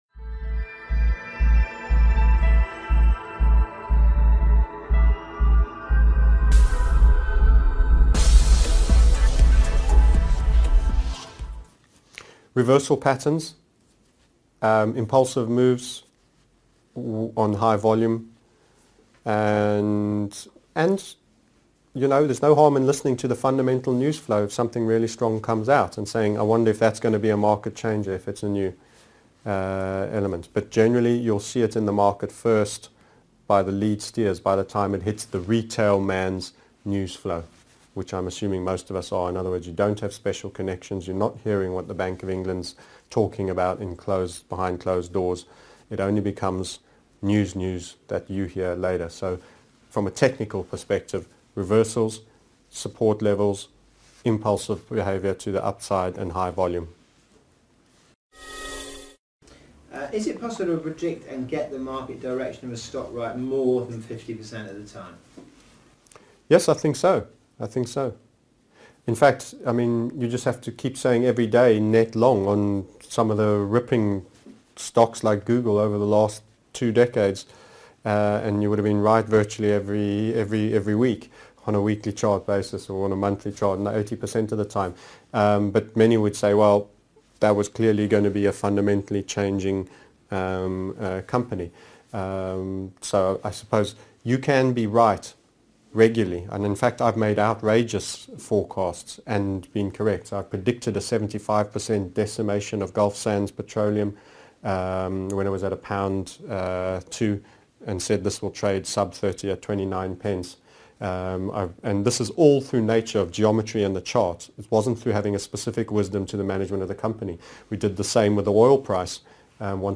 16 How can you detect trend changes early TMS Interviewed Series 16 of 32